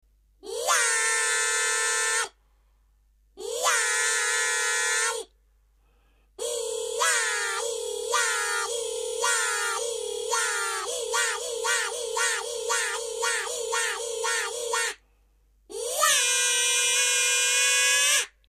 イヤハーイ笛